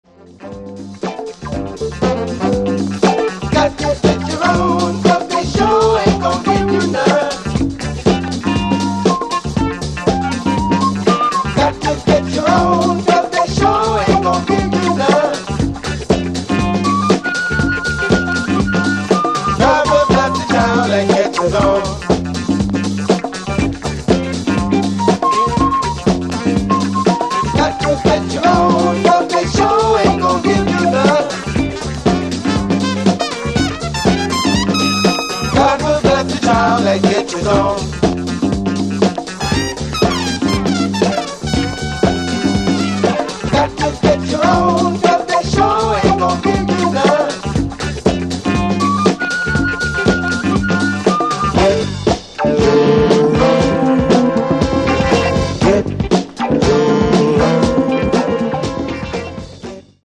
Re-Edits